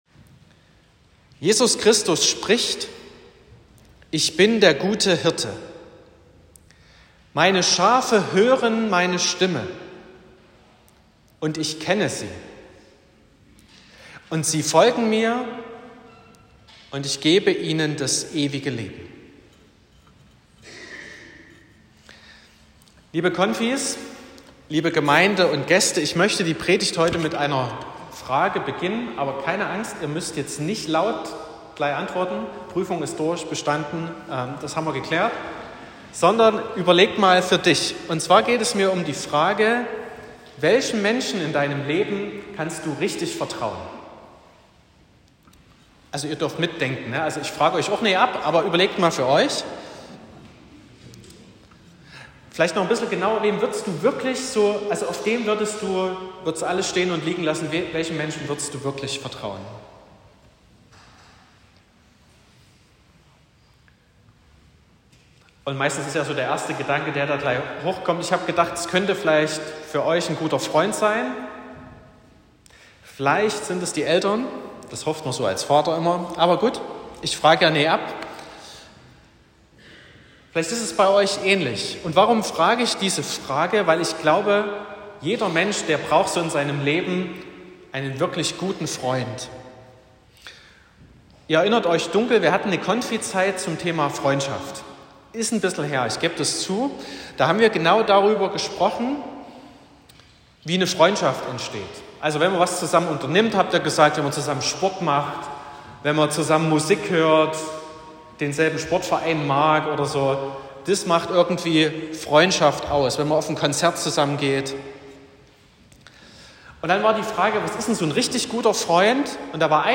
04.05.2025 – Konfirmationsgottesdienst
Predigt und Aufzeichnungen